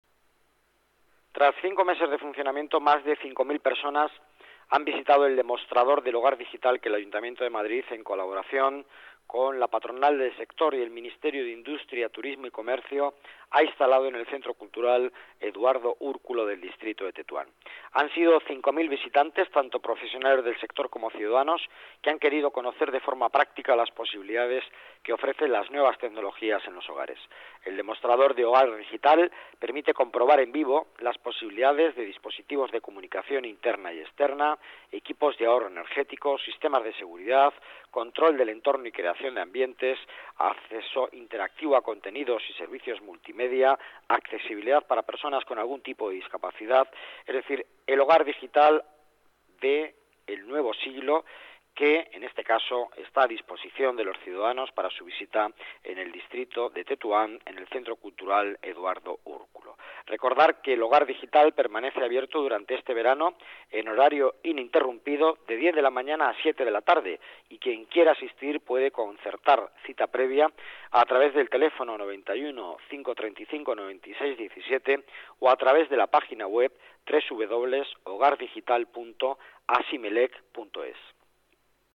Nueva ventana:Declaraciones delegado Economía y Empleo, Miguel Ángel Villanueva: hogar digital